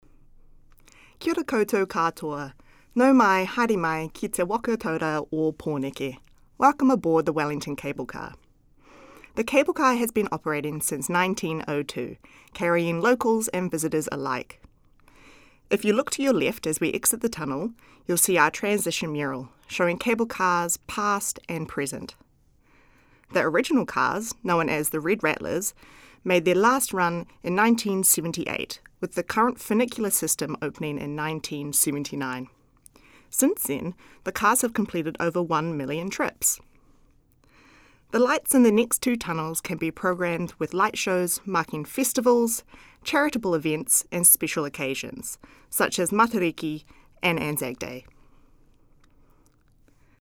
Audio Commentary
Departing From Lambton Quay